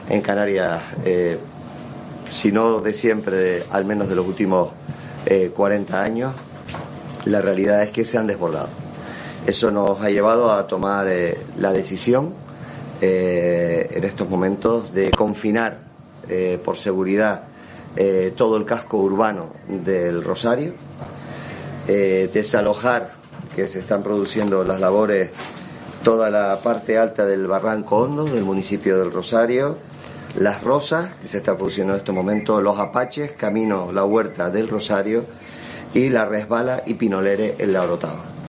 Fernando Clavijo, presidente de Canarias